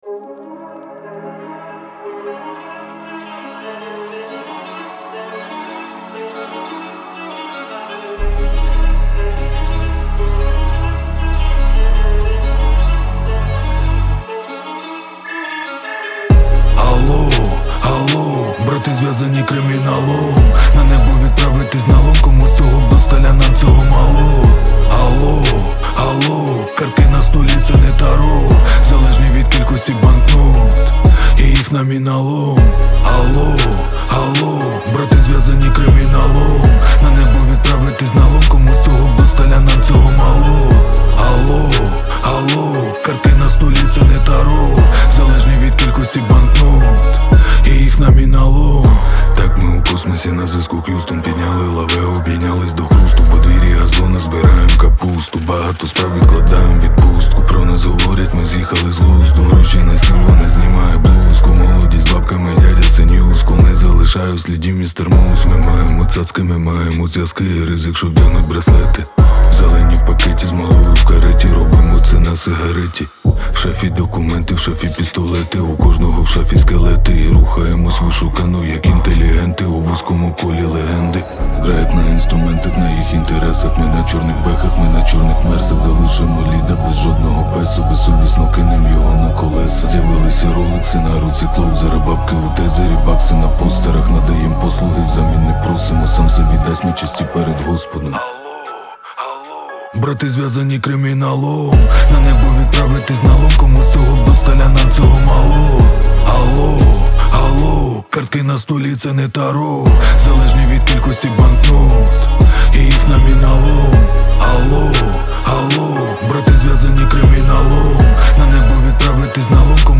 Жанр: Реп / хіп-хоп